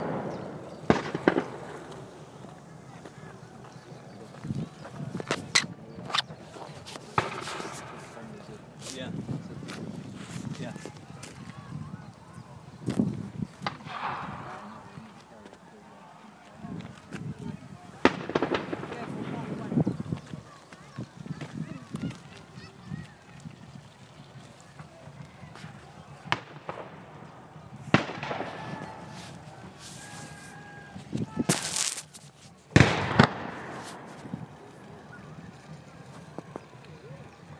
Cannon and water